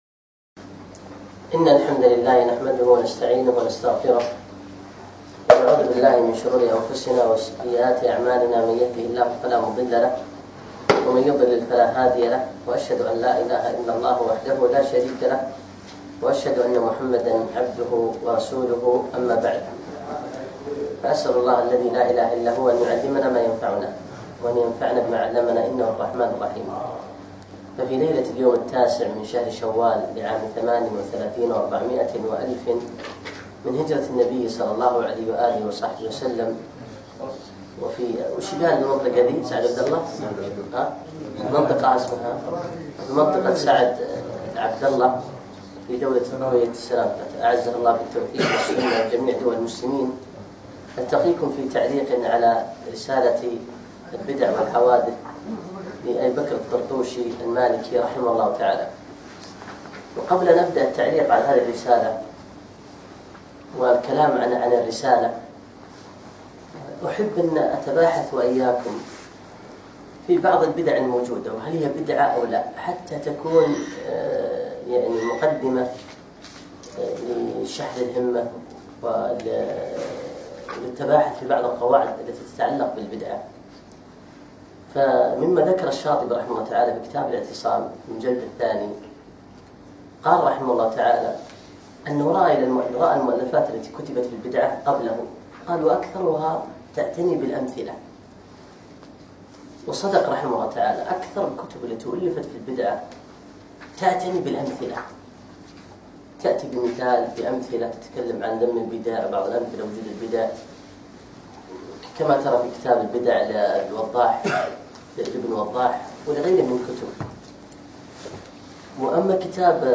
يوم الأحد 8 شوال 1438 الموافق 2 7 2017 في ديوانية مشروع الدين الخالص سعد العبدالله
الدرس الأول